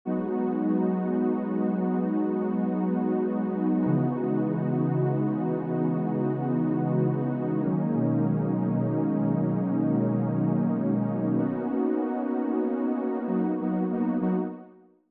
14 pad A1.wav